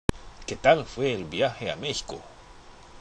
聞き取りテストです！
スペイン語で歌っているような、いないような（笑）・・